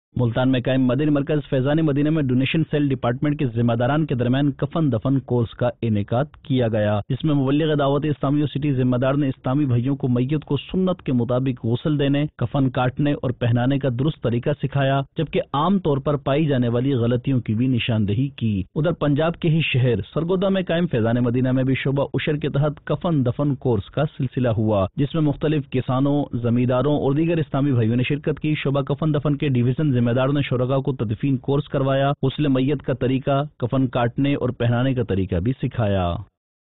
News Clips Urdu - 27 December 2022 - Donation Cell Department Kay Zimadaran Main Kafan Dafan Course Ka Ineqad Jan 3, 2023 MP3 MP4 MP3 Share نیوز کلپس اردو - 27 دسمبر 2022 - ڈونیشن سیل ڈیپارٹمنٹ کے ذمہ داران میں کفن دفن کورس کا انعقاد